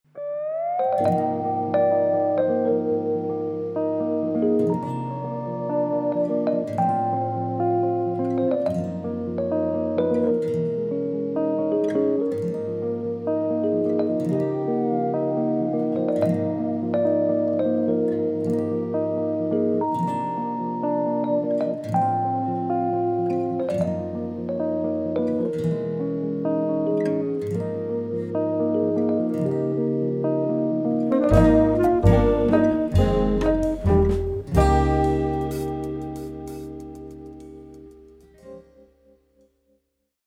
sax
guitar
Fender Rhodes
bass
drums